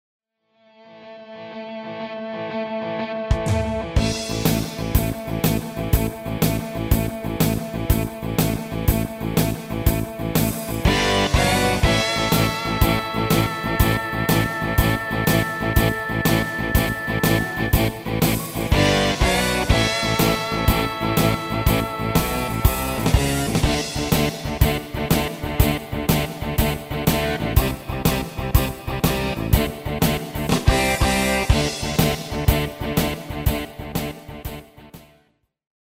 Demo/Koop midifile
Genre: Nederlands amusement / volks
- Géén tekst
- Géén vocal harmony tracks